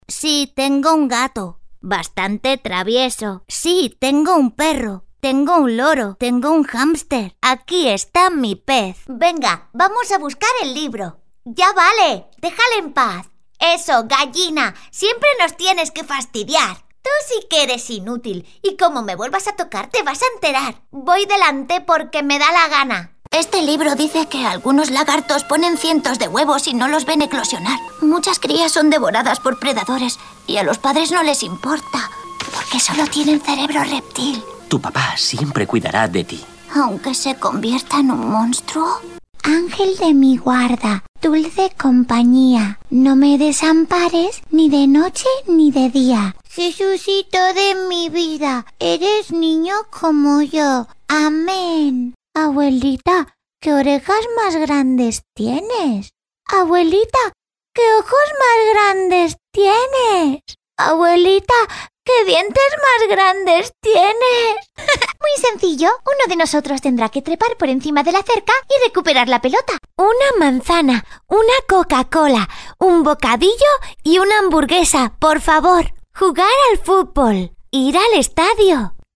Actriz de doblaje y cantante con registro infantil, adolescente, adulto e incluso abuelitas.
Sprechprobe: eLearning (Muttersprache):
My voice is warm and clear for narrations, fun and young for commercials, professional and smooth for presentations.